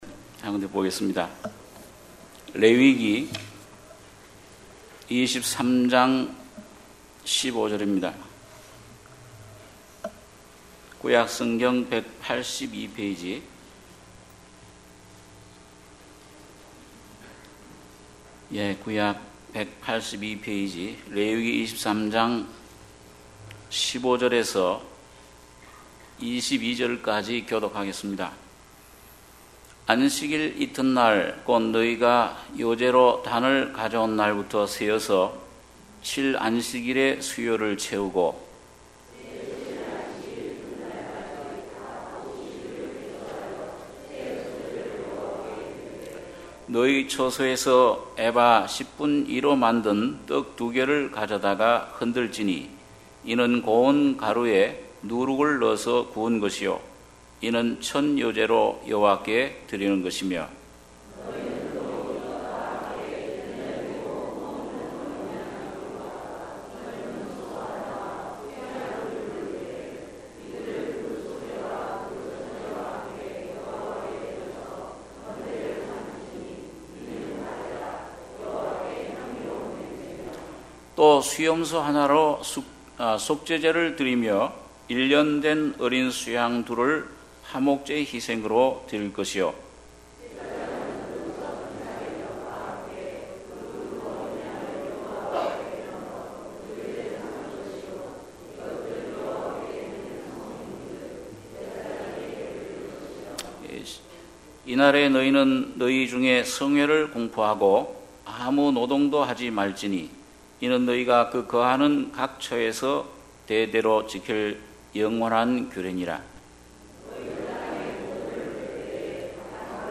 주일예배 - 레위기 23장 15-22절